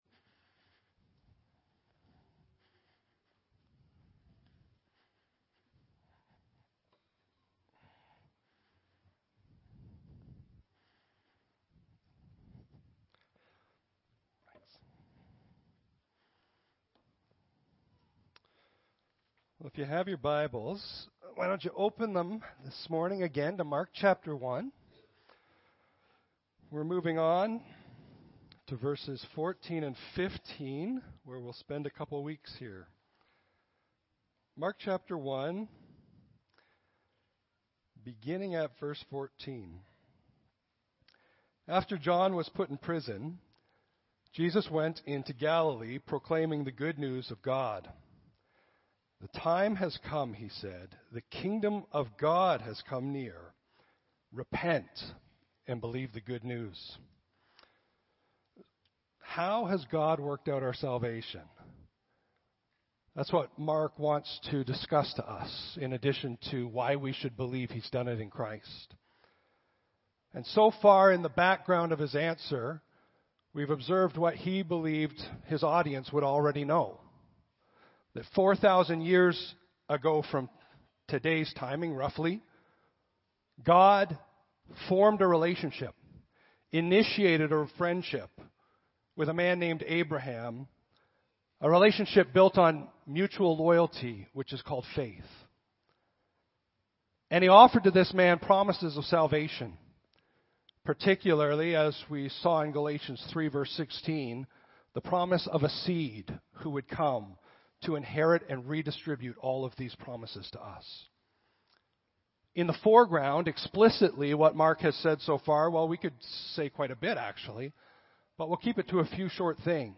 Audio Sermon Library The Gospel of Mark, Part 12-Two Covenants, One Calling.